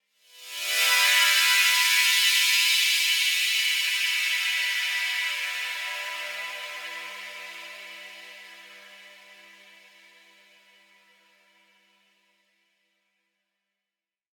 SaS_HiFilterPad02-E.wav